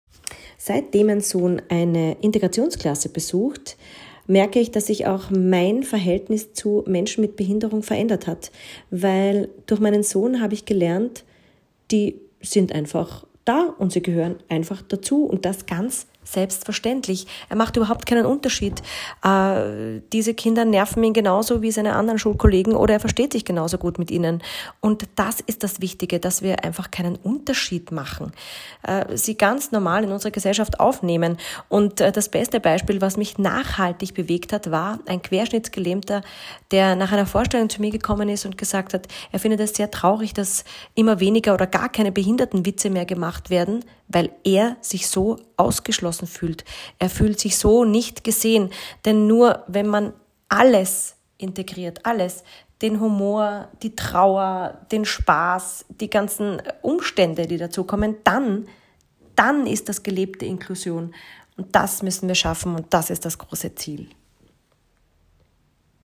Bewegende Botschaften zum Thema Inklusion, gesprochen von Menschen aus Kunst, Kultur, Politik und Wissenschaft.